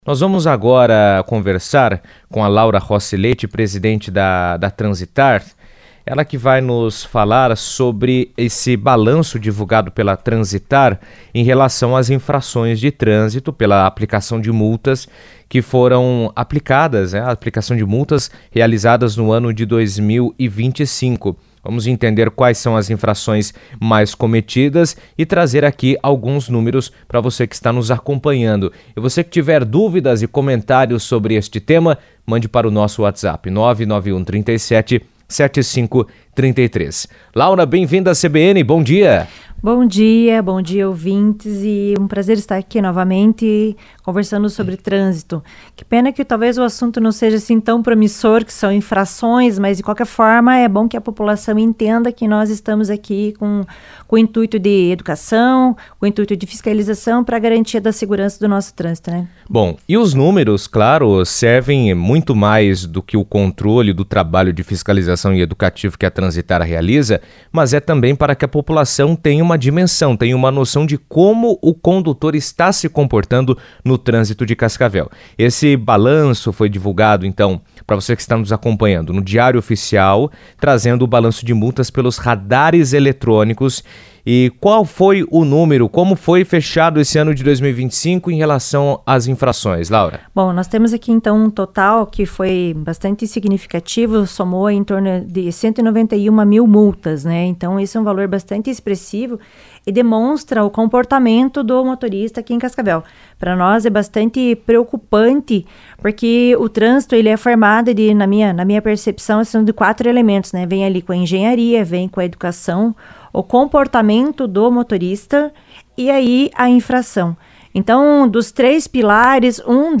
O balanço anual divulgado pela Transitar aponta que o excesso de velocidade foi a infração mais registrada em 2025, superando outras violações de trânsito. Em entrevista à CBN, a presidente da Transitar, Laura Rossi Leite, ressaltou a importância da fiscalização para a segurança nas vias e reforçou a necessidade de conscientização dos motoristas sobre os limites de velocidade.